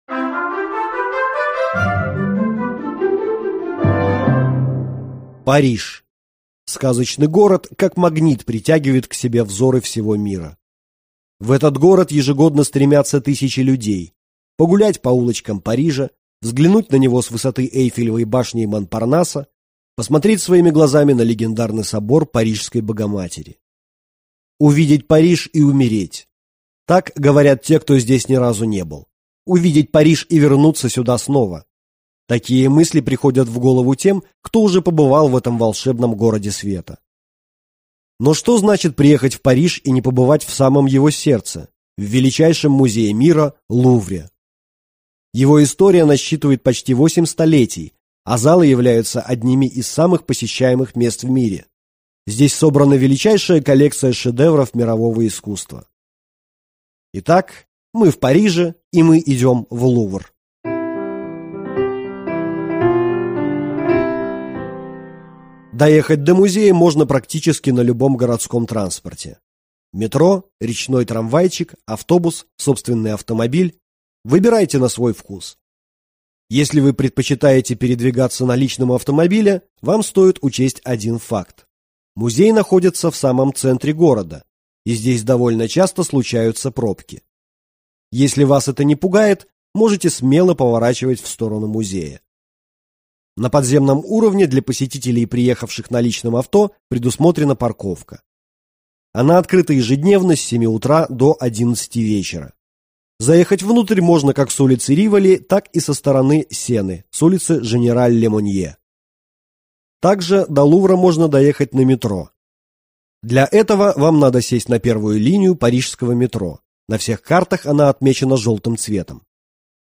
Аудиокнига Путеводитель по Лувру | Библиотека аудиокниг